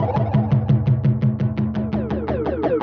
Index of /musicradar/rhythmic-inspiration-samples/85bpm
RI_DelayStack_85-10.wav